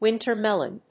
アクセントwínter mélon